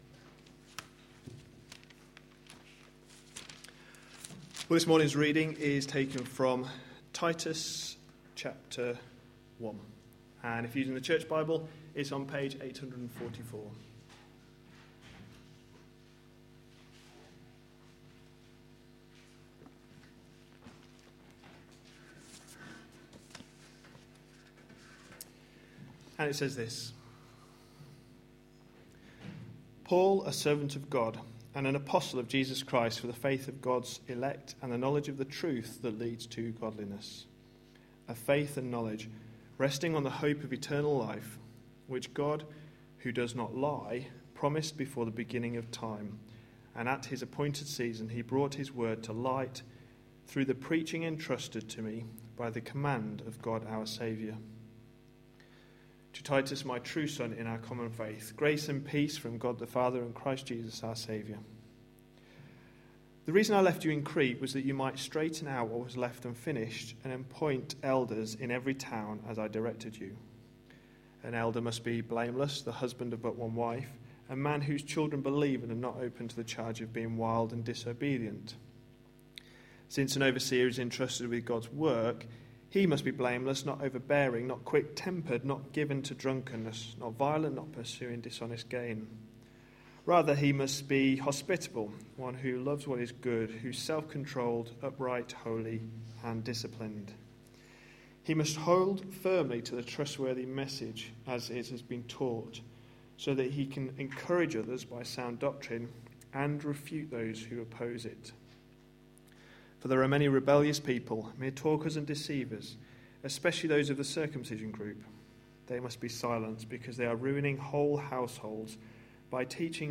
A sermon preached on 25th May, 2014, as part of our Changing the way you think series.